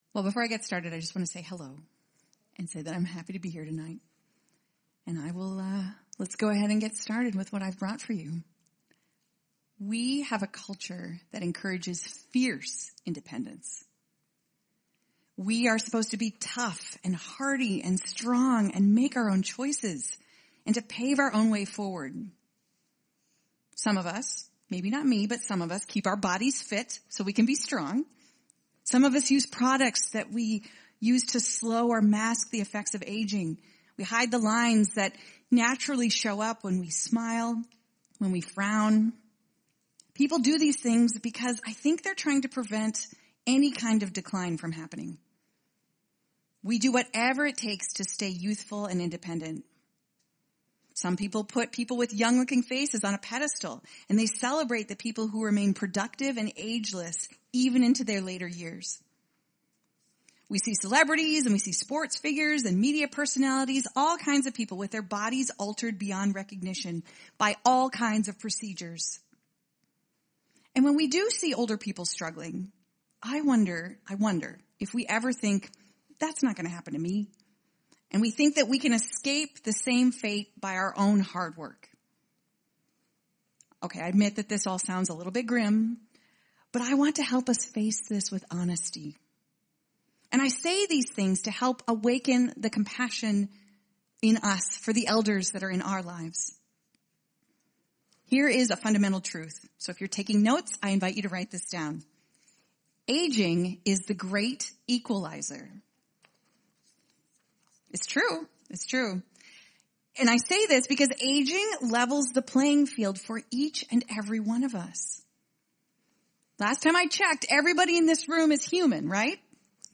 Weekly messages from Emmanuel Covenant Church, Twin Cities, MN.